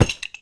wrench_hit_card1.wav